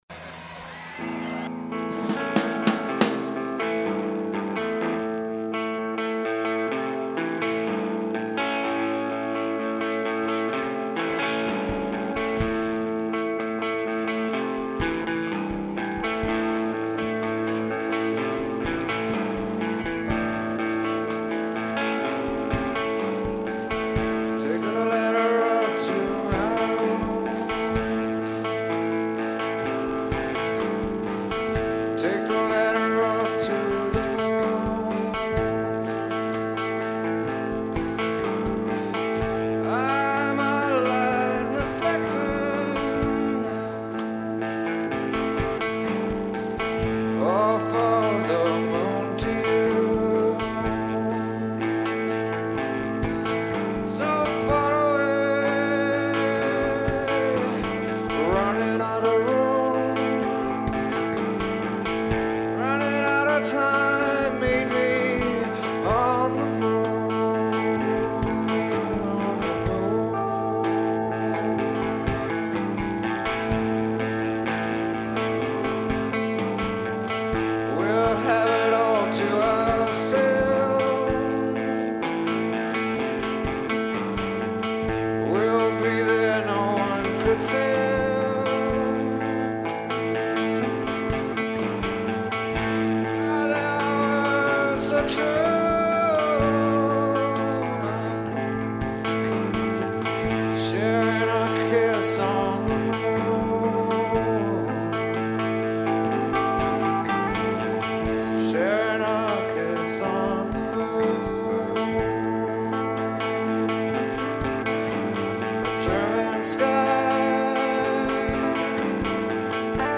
Rock Im Park Festival: Nurnberg, Germany